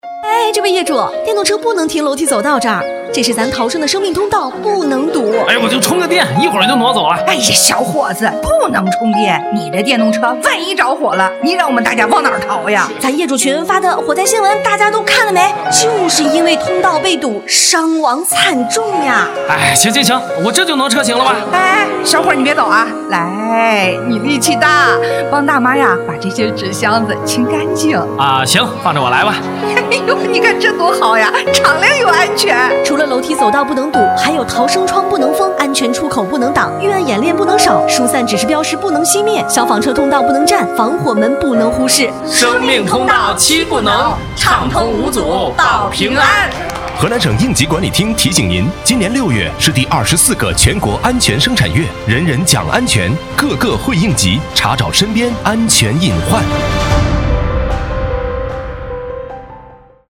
公益广告-应急管理厅安全生产月